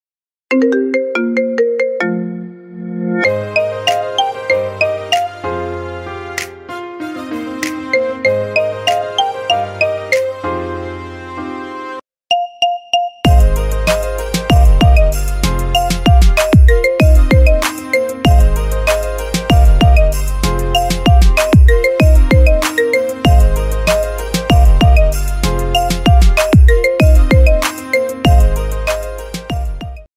Categoría Marimba Remix